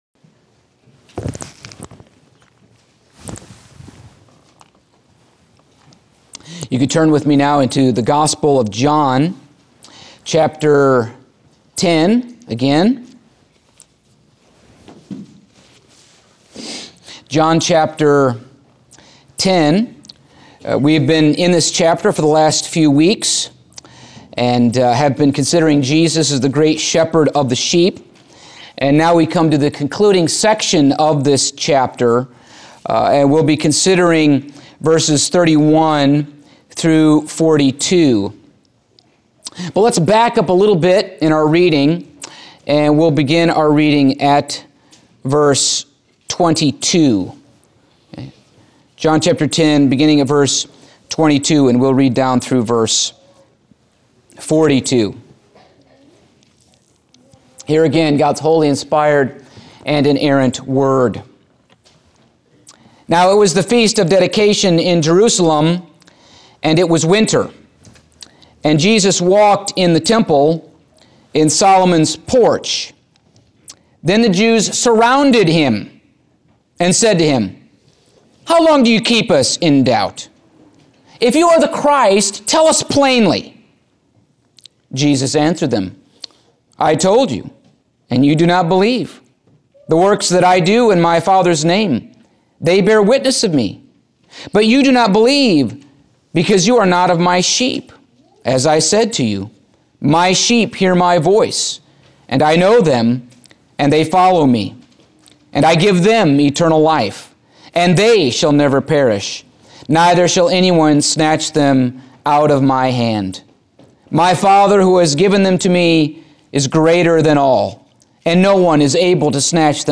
Passage: John 10:31-42 Service Type: Sunday Morning